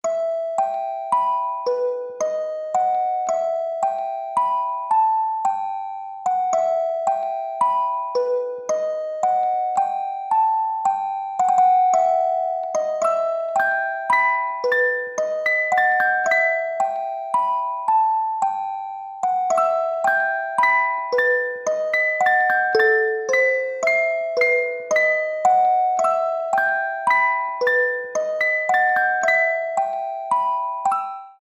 без слов
музыкальная шкатулка